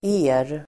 Uttal: [e:r]